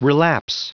Prononciation du mot relapse en anglais (fichier audio)
Prononciation du mot : relapse